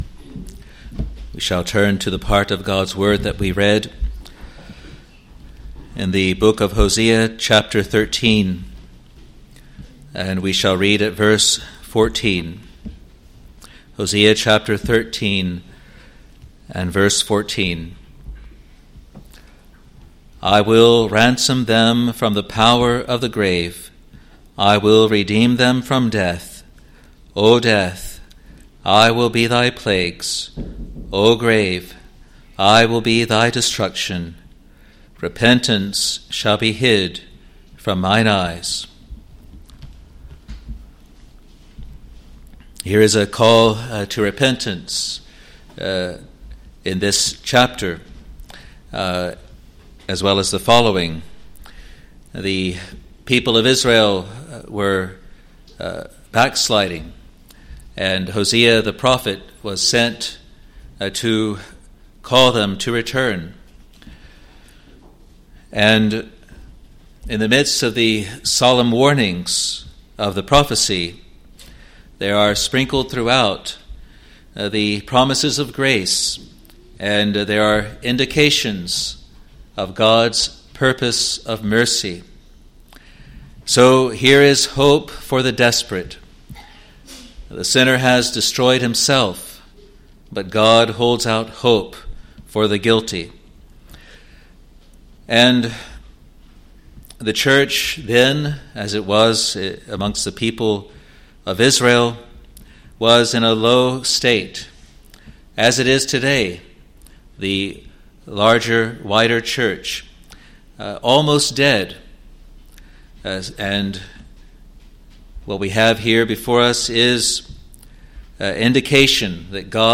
Sermons | Free Presbyterian Church of Scotland in New Zealand